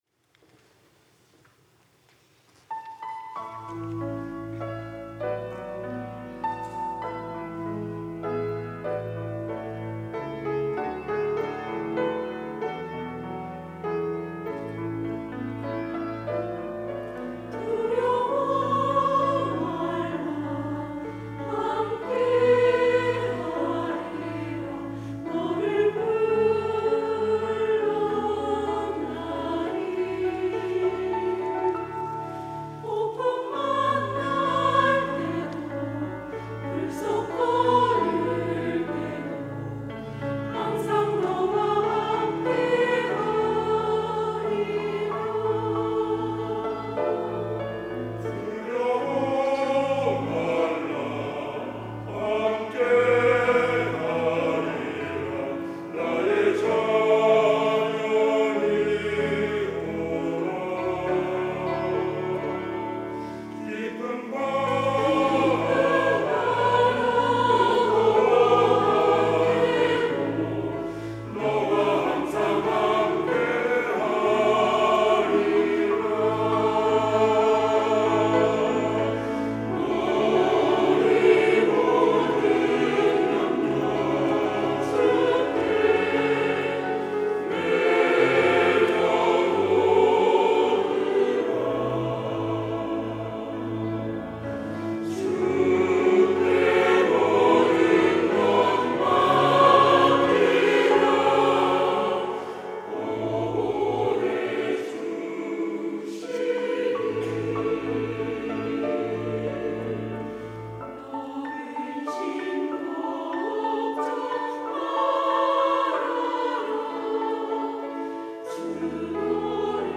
시온(주일1부) - 두려워 말라 너는 내 것이라
찬양대